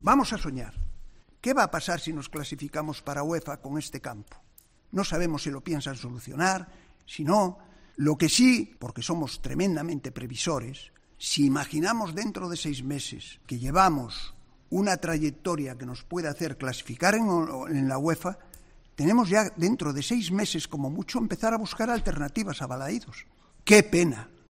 Carlos Mouriño ha comparecido ante los medios de comunicación en A Sede para repasar lo más destacado de la actualidad del Celta.